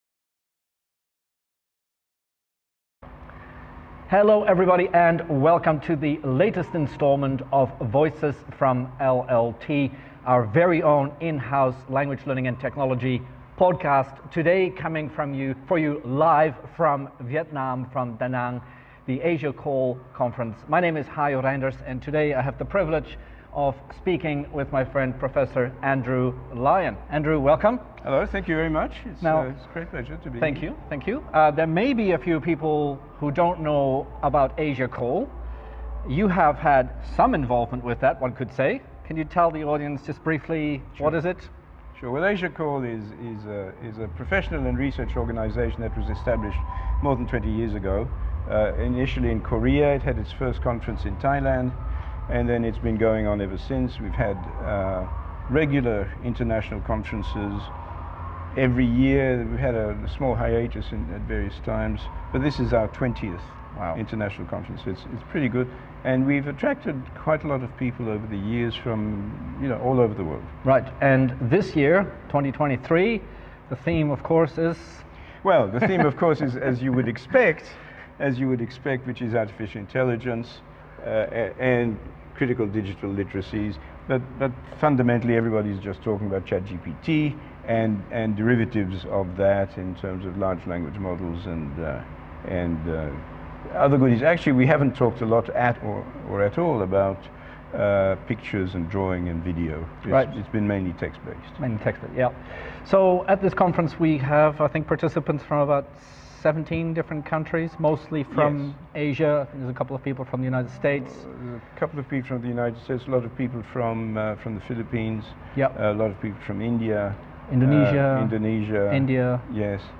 A conversation
at the Asia CALL conference held in Da Nang, Vietnam.